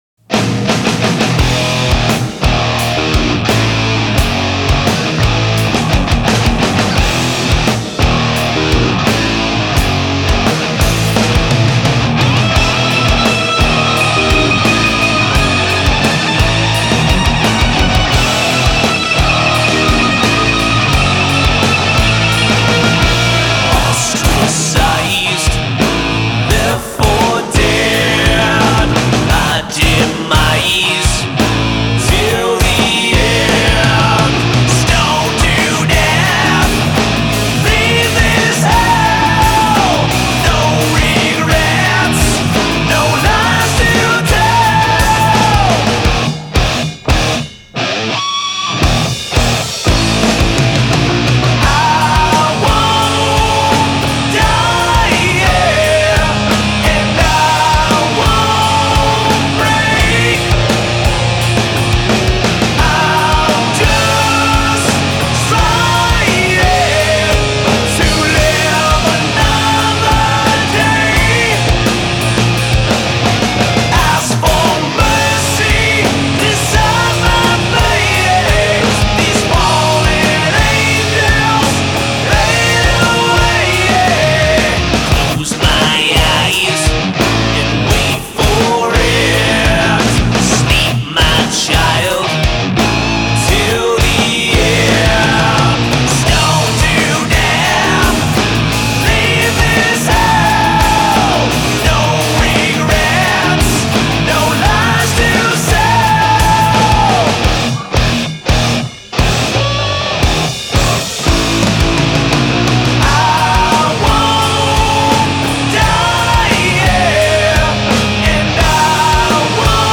fast rhythms, squealing vocals & winding guitar solos